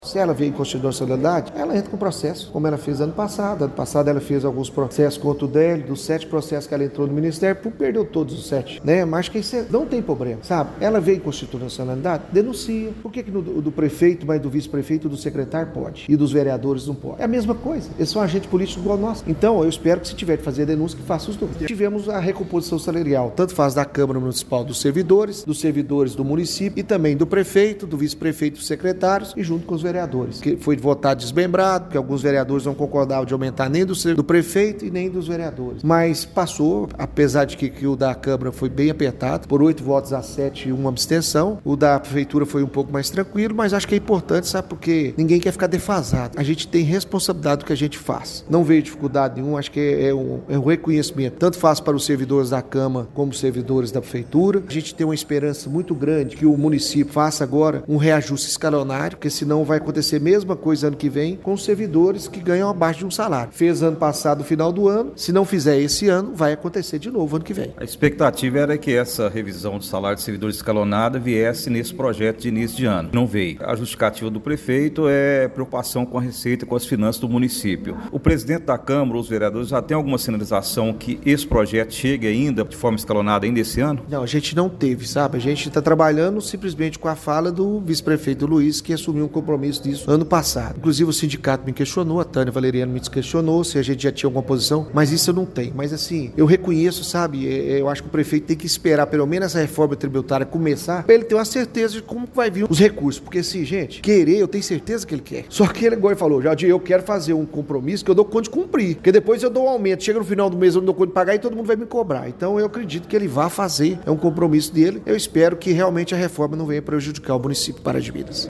Em entrevista coletiva, ele afirmou que o Projeto de Lei nº 05/2026 é juridicamente válido, ressaltando que a proposta tramitou dentro da legalidade e contou com pareceres técnicos favoráveis.